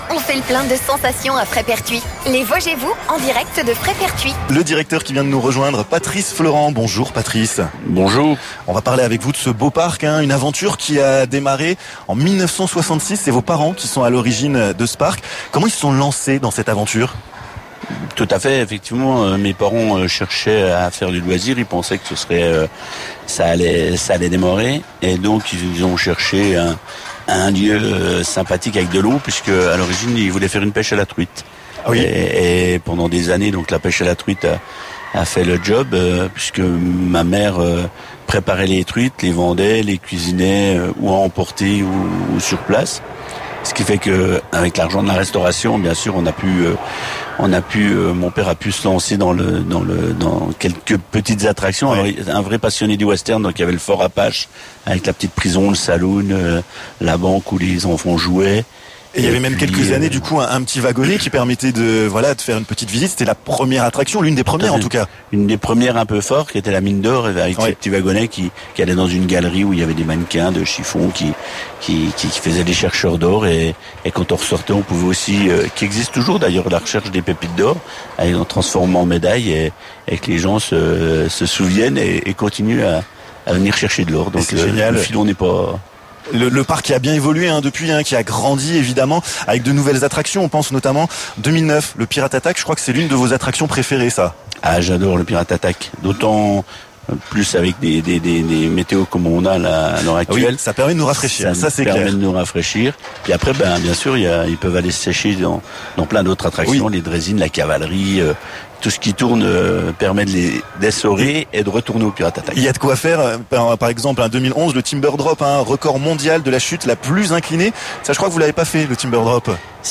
Mardi 11 juillet, Vosges FM était en direct du parc d'attractions Fraispertuis City! De nombreux invités sont passés à notre micro pour nous expliquer l'histoire, les nouveautés, et les découvertes du parc! Une partie de l'équipe a même testé la nouvelle attraction "El Molcajete".